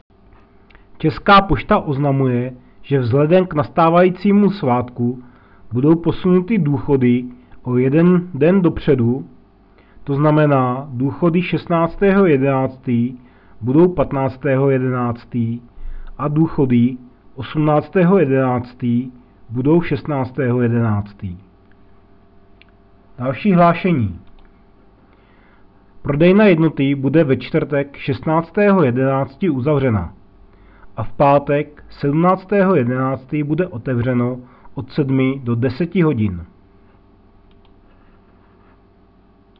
del – Hlášení rozhlasu – 2. stránka – Obec Velenov